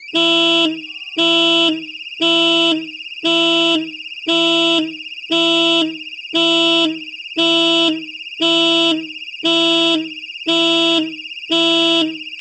Diverse Alarmsignale
8-diverse-alarmsignale